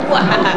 Evil LOL